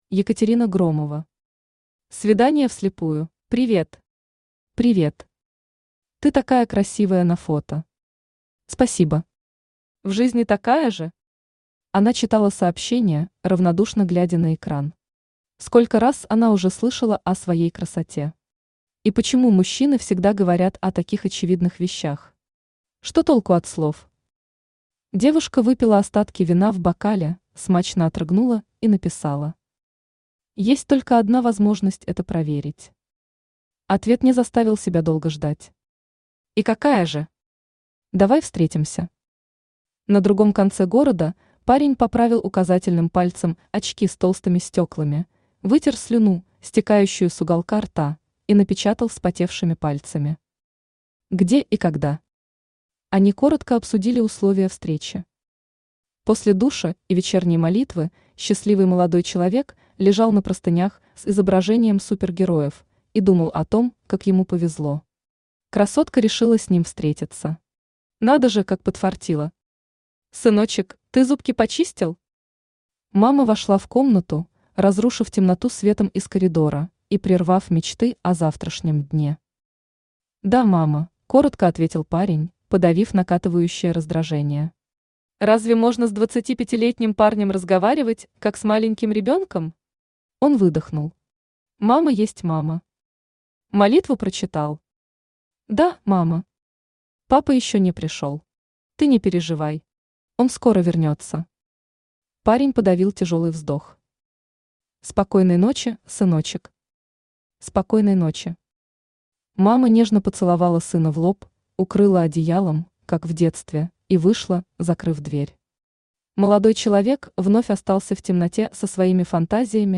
Аудиокнига Свидание вслепую | Библиотека аудиокниг
Aудиокнига Свидание вслепую Автор Екатерина Громова Читает аудиокнигу Авточтец ЛитРес.